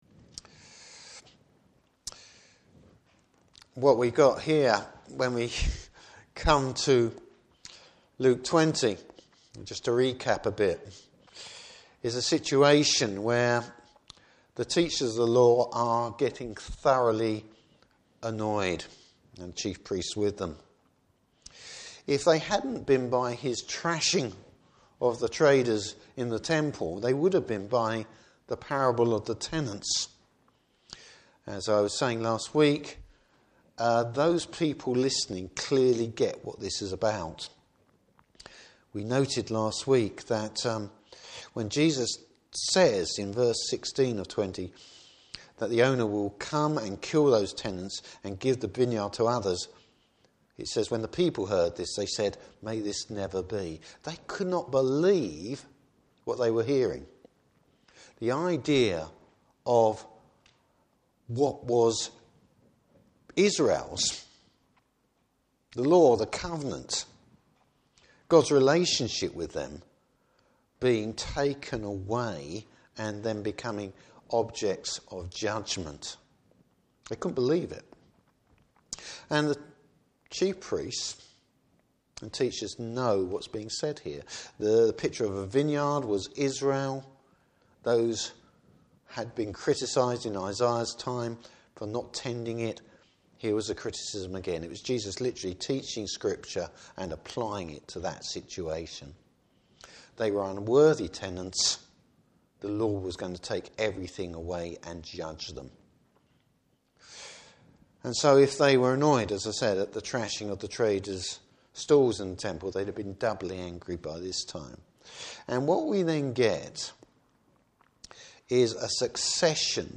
Service Type: Morning Service Jesus’ authority is displayed for all to see!